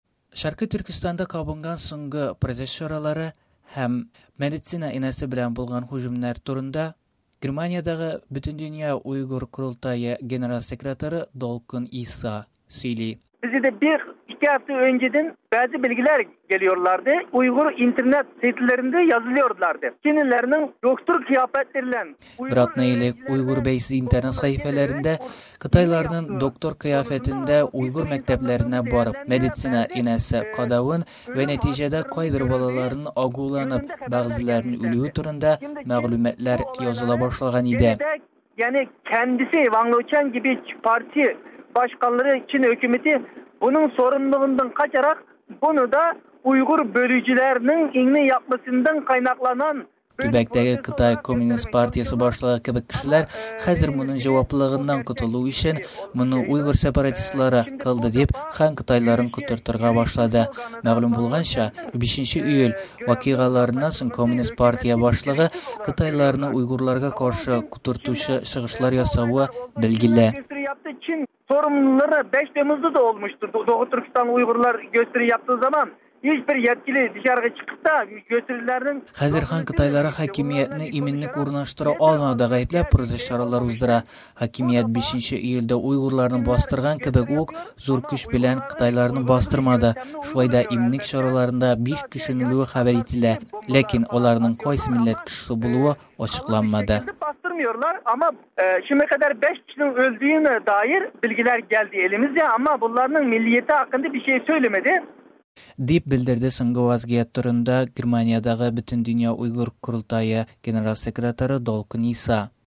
Öremçedäge soñgı protest häm enäle höcüm turında Bötendönya uygır qorıltayı urınbasarı Dulqın Isa belän äñgämä